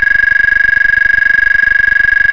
Sirena Multitonal 8 Sonidos
102dB